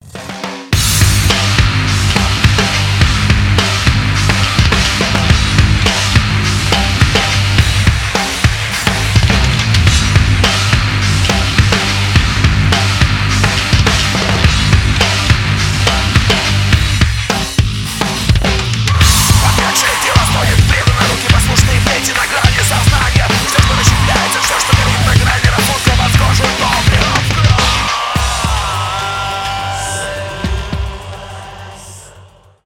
рок , experimental , industrial metal , мощные